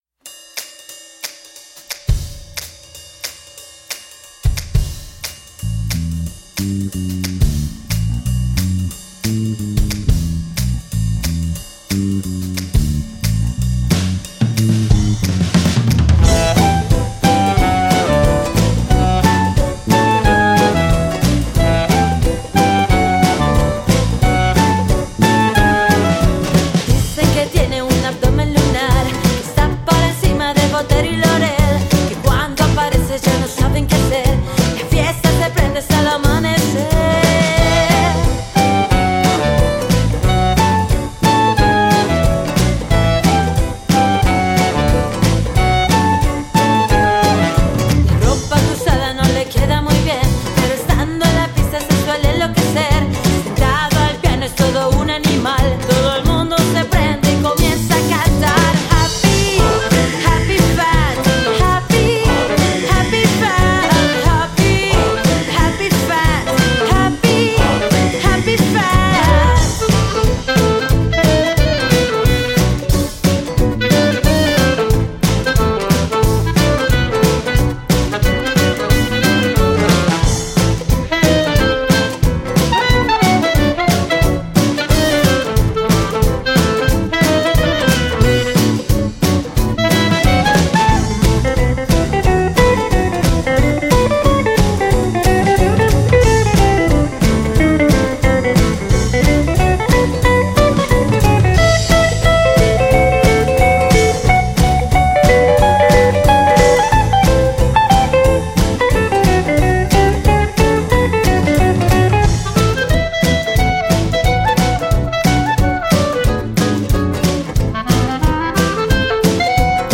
En la batería esta el ritmo y el swing
el bajo camina, movimiento sin fin.
Suena la guitarra hermosos acordes
el saxo desgarra gran brillo de bronces.
Y en el clarinete sonido gitano
Jazz rock